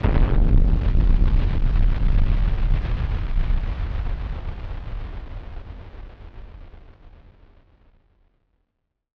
BF_DrumBombC-01.wav